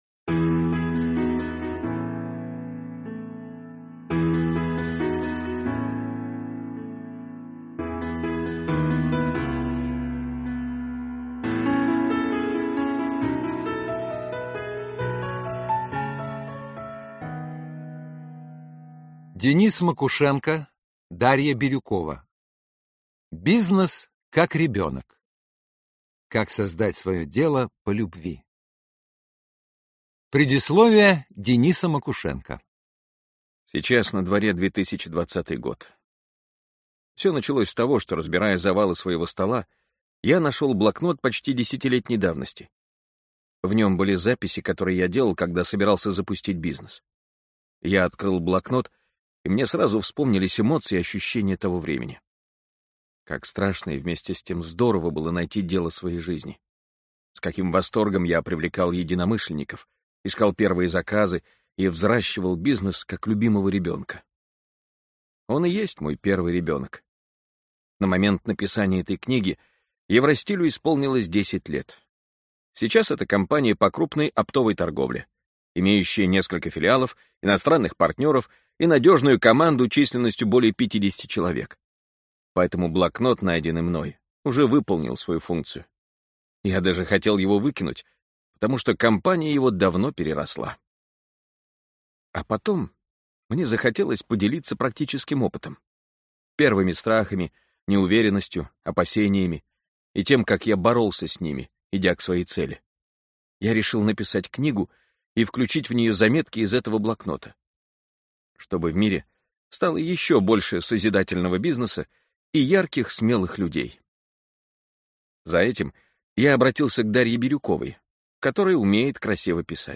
Аудиокнига Бизнес как ребенок. Как создать своё дело по любви | Библиотека аудиокниг